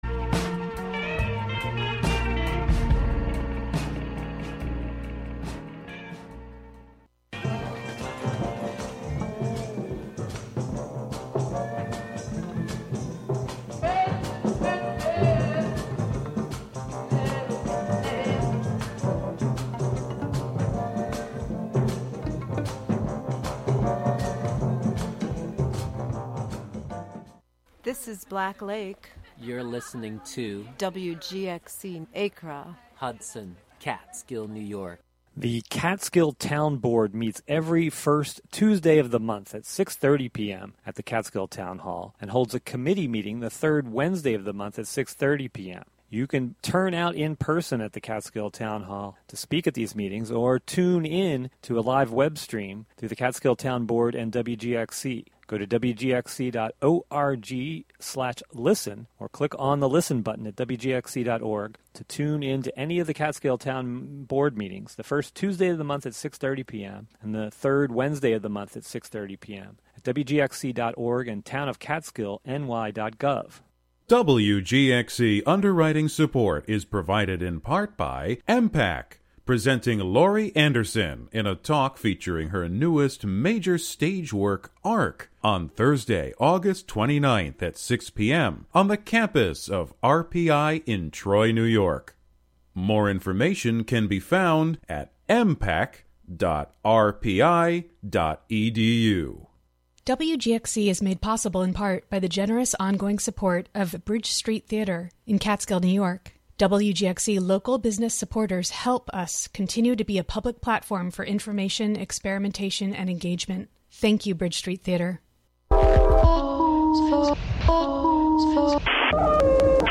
Unearthing a 1996 performance she gave in Albany, this month’s program celebrates the work of Anne Waldman.
Blending words and sounds, bringing to the airwaves live performances as well as field and studio recordings by writers the host has crossed paths with over the course of a quarter century.